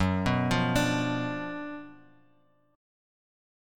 F#M7sus4#5 chord